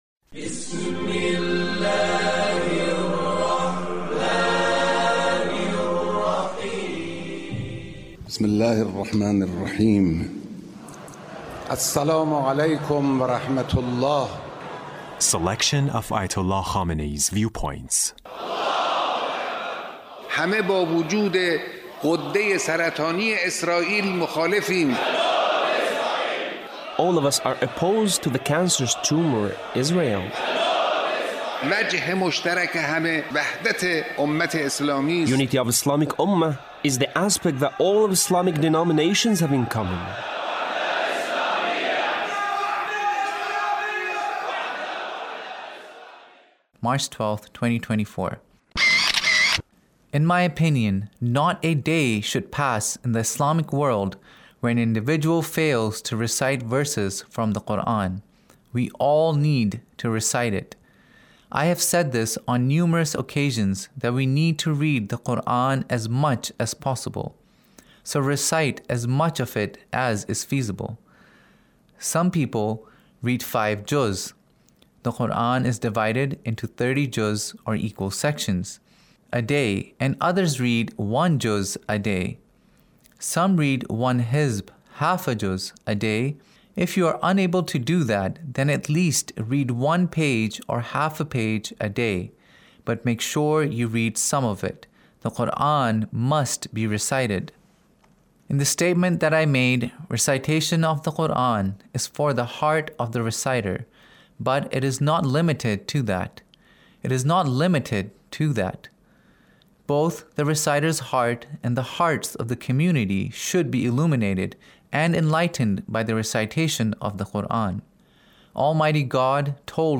Leader's Speech (1939)